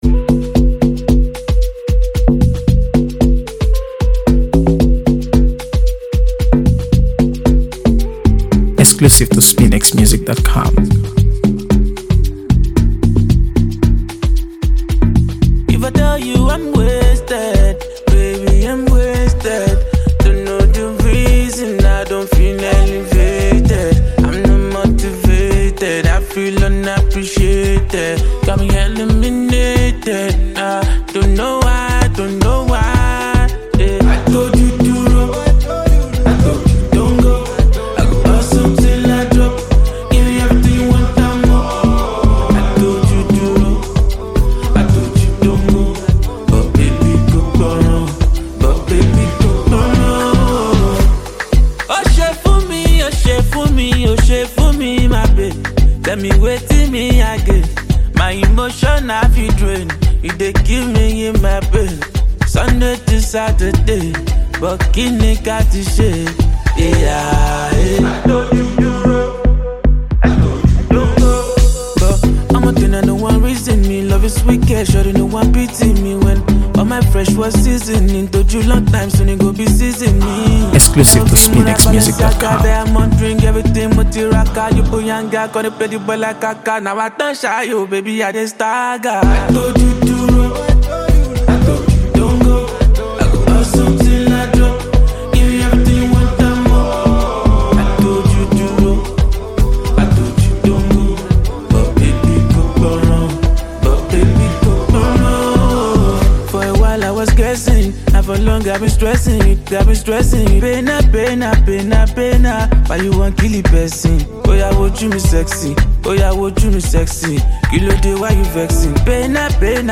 AfroBeats | AfroBeats songs
The beat is bouncy yet mellow
laid-back, vibey, and full of personality.